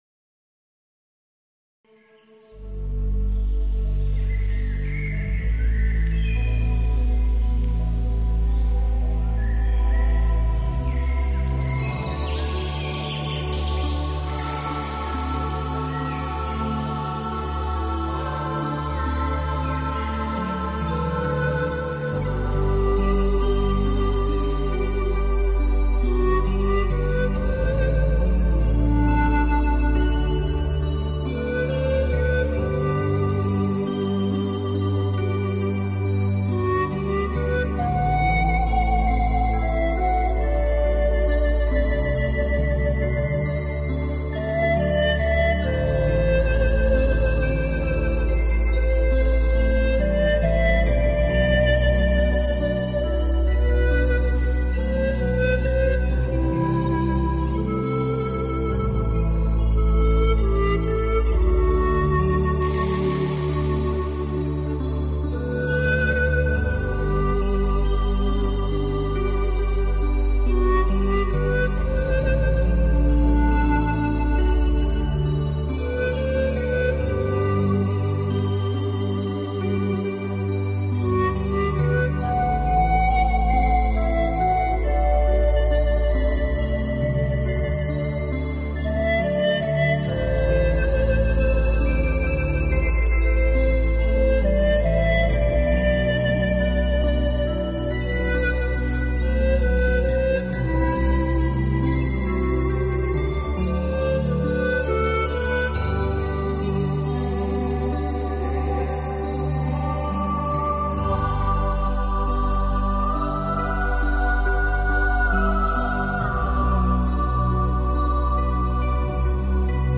音乐流派：  Nature Music